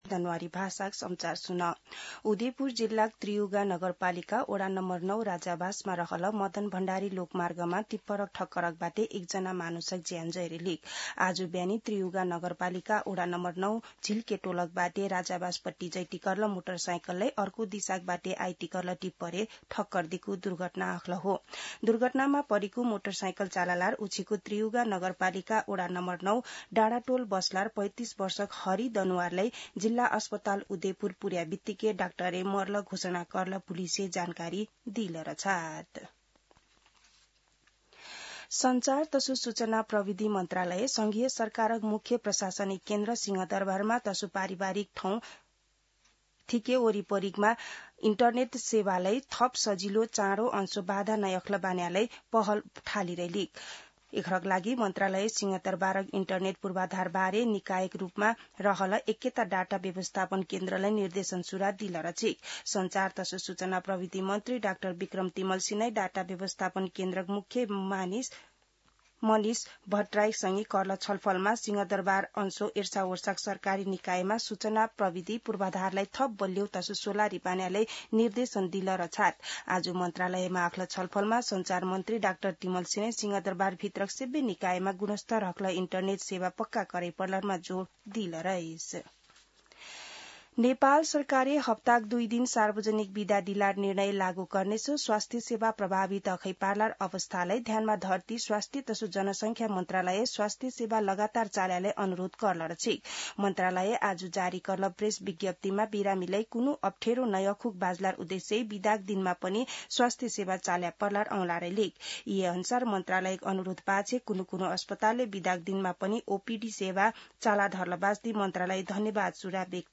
दनुवार भाषामा समाचार : ३० चैत , २०८२
Danuwar-News-30.mp3